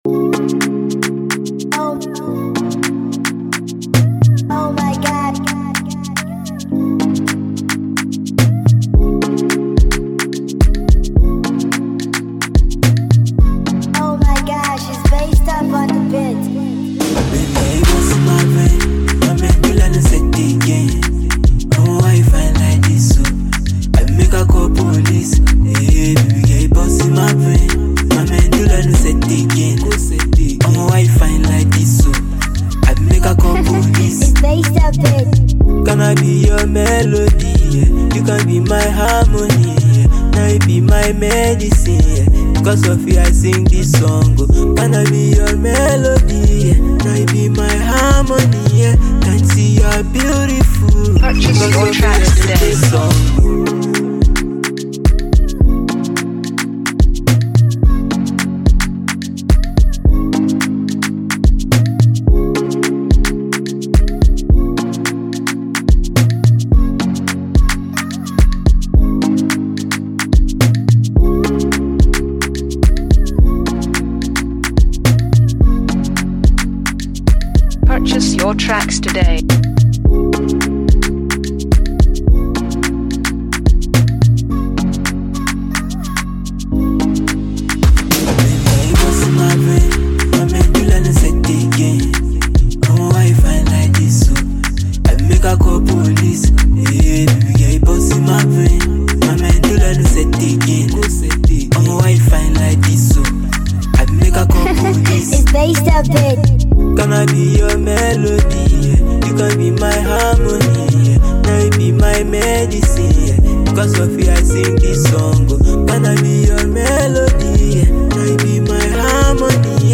instrumental Free Beat With Hook